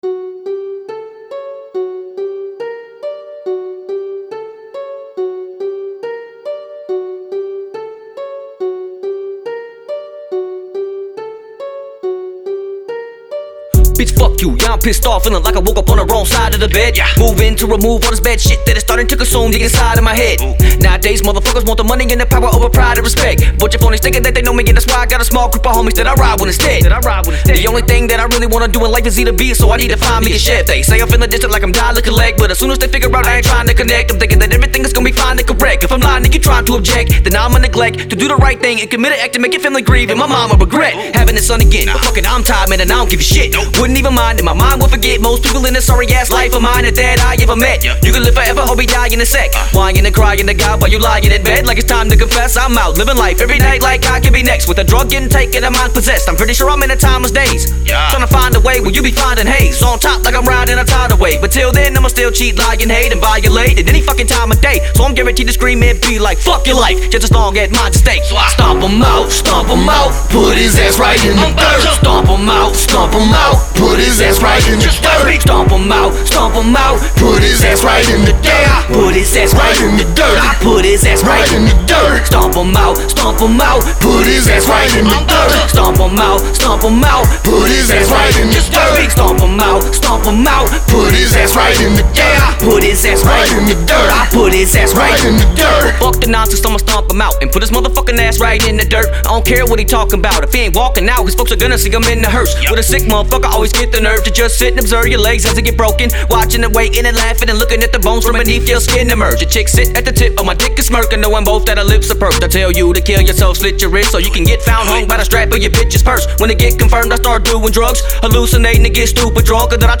bass heavy beats